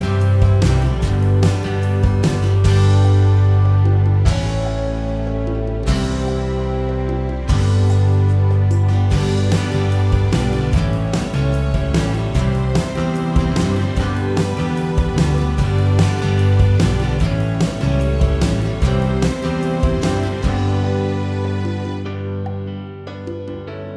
Minus All Guitars Except Acoustic Pop (1990s) 3:33 Buy £1.50